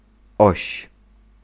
Ääntäminen
Ääntäminen Tuntematon aksentti: IPA: [ɔɕ] Haettu sana löytyi näillä lähdekielillä: puola Käännös Konteksti Substantiivit 1. axis geometria, matematiikka 2. pivot 3. axle Suku: f .